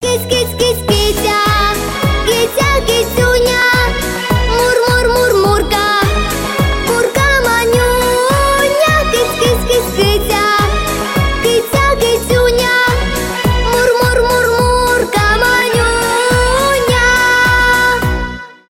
детские , зарубежные , поп , украинские